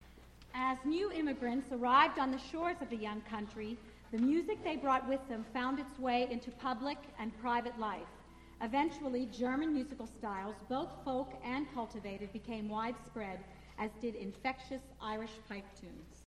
Spoken intro for Brian Boru's March, Planxty Iwin, and an unidentified German song